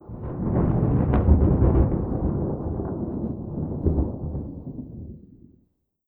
tenkoku_thunder_medium05.wav